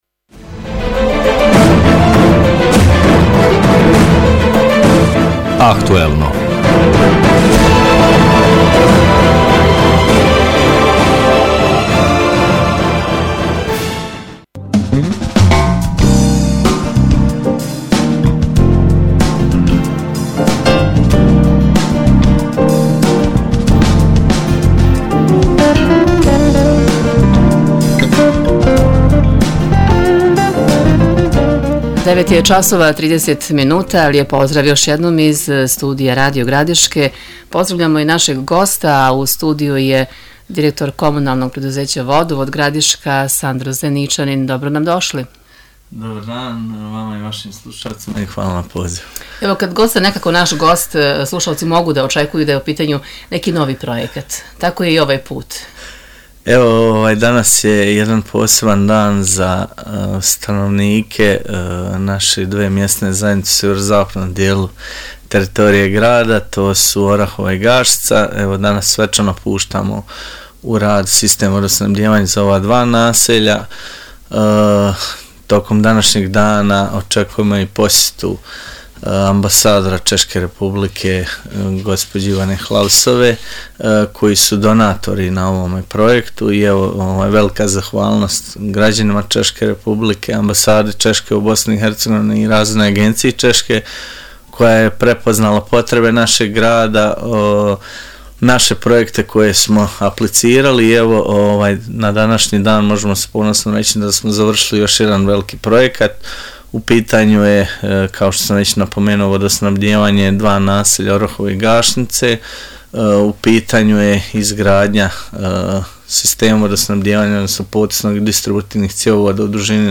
gostujući u emisiji „Aktuelno“ Radio Gradiške